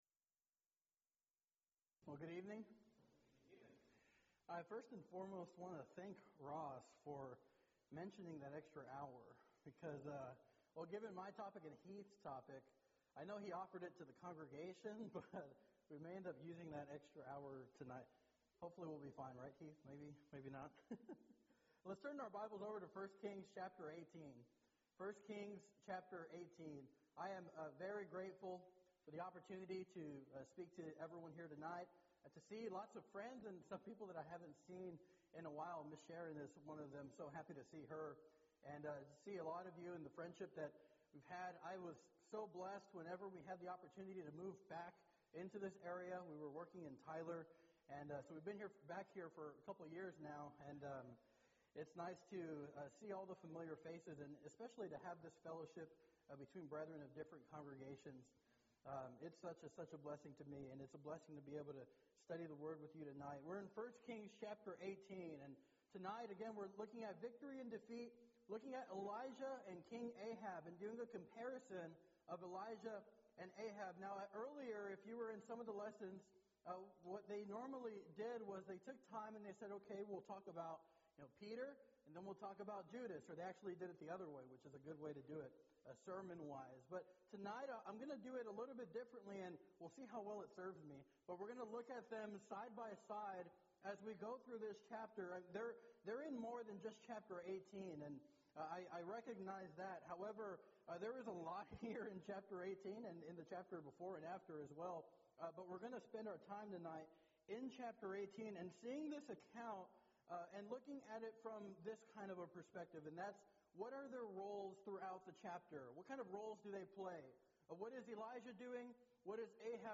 Event: 3rd Annual Colleyville Lectures
lecture